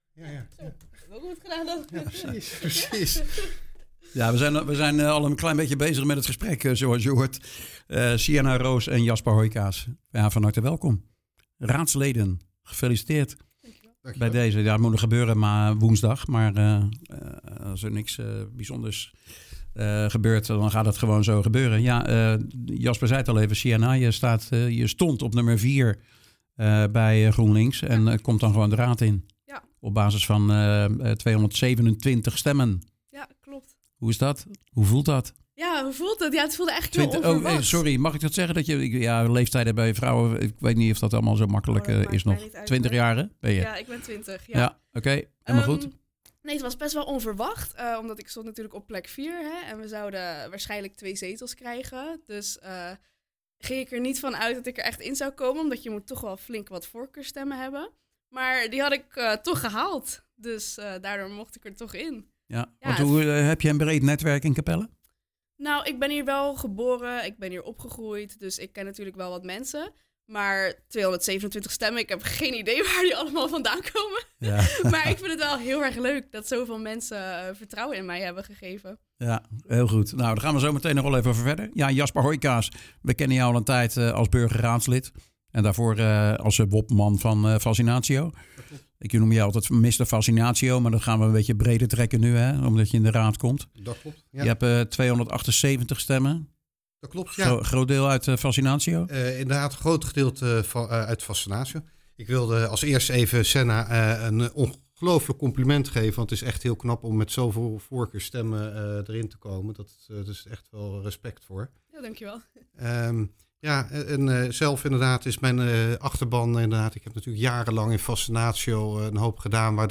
in gesprek met Sienna Roos en Jasper Hooijkaas over hun achtergrond en ambities.